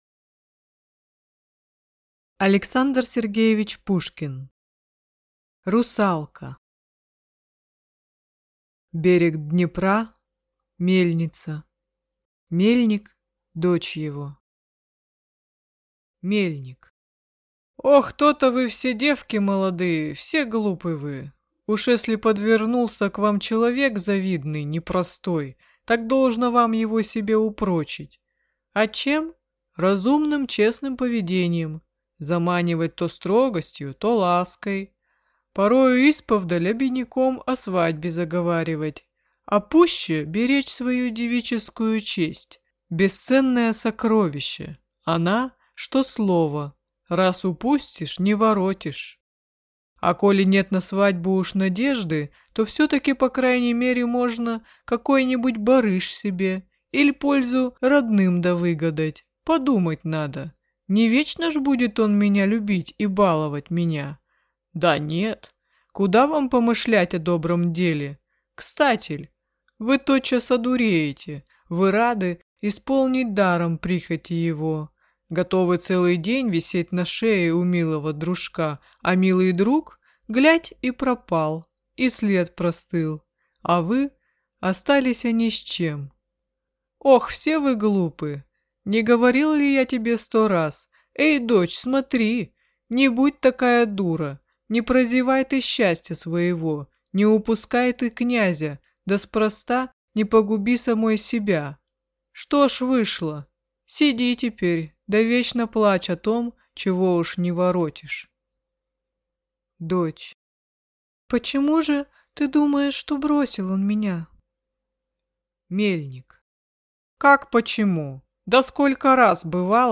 Аудиокнига Русалка | Библиотека аудиокниг